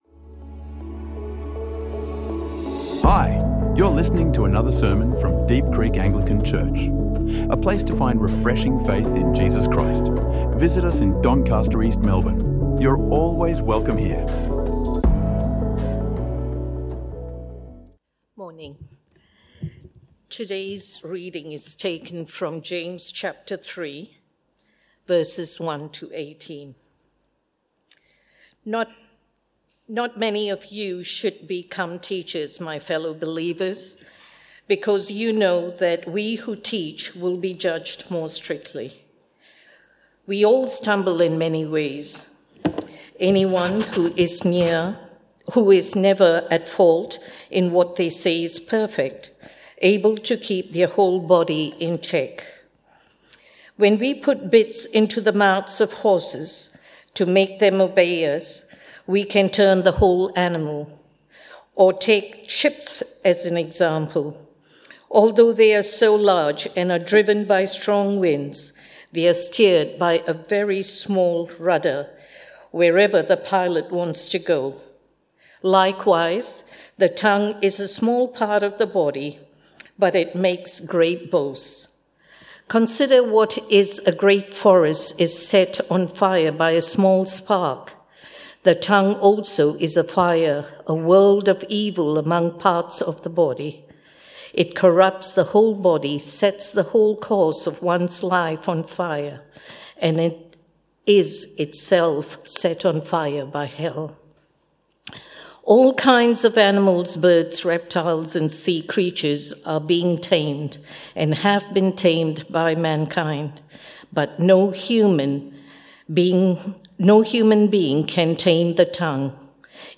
Taming the Tongue | Sermons | Deep Creek Anglican Church